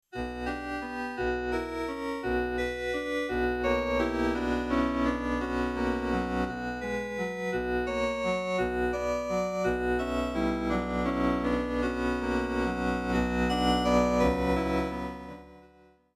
Очевидно, что профессиональный и «игровой» FM-синтез разделяет огромная пропасть качества.
16sborgan.mp3